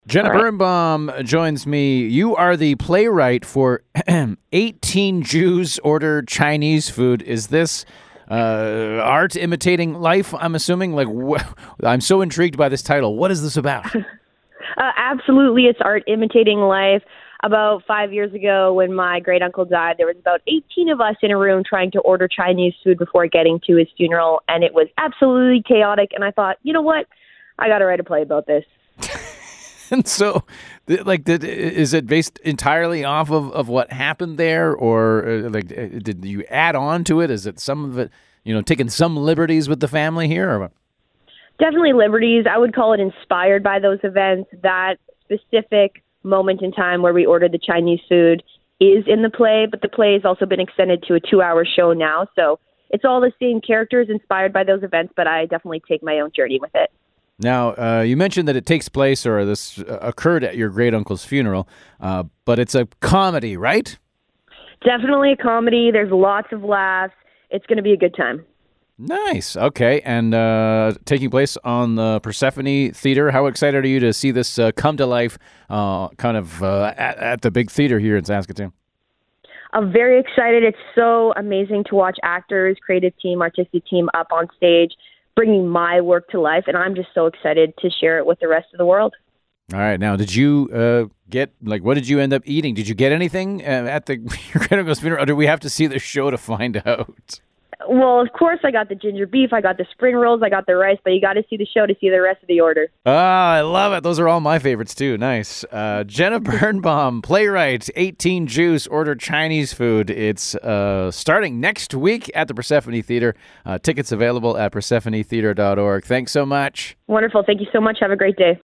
talks to playwright